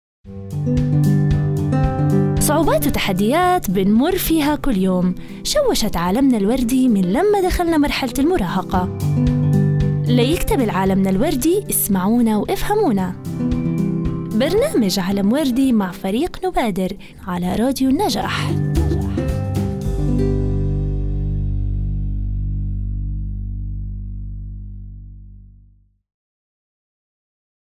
مقطع تشويقي بودكاست عالم وردي
بودكاست عالم وردي هو برنامج مميز يقدمه مجموعة من اليافعات المشاركات ضمن مشروع نبادر في مركز حكاية لتنمية المجتمع المدني، يتميز هذا البودكاست بتضمينه سكيتشات تمثيلية درامية تعكس تجارب الفتيات واليافعات في مواجهة قضايا مثل التمييز بين الذكور والإناث، عدم الثقة بالبنات، تأثير السوشل ميديا على حياتهن، العنف ضد البنات والعصبية.